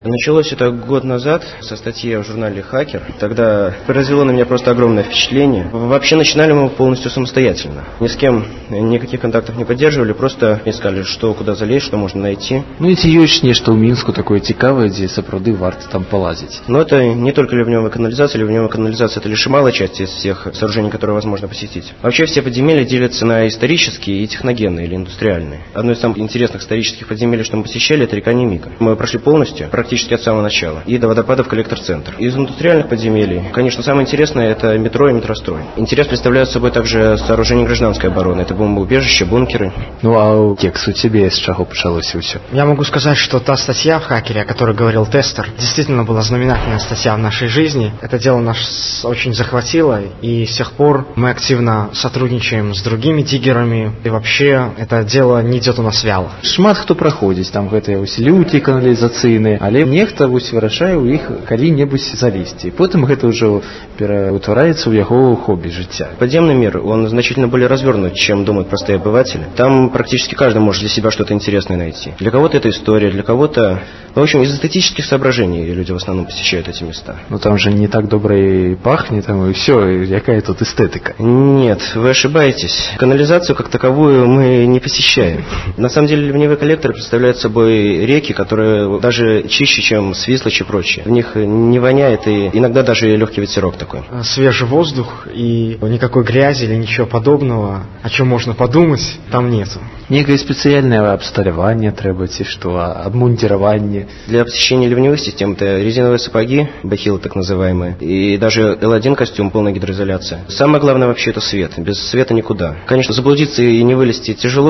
Интервью на радио Столица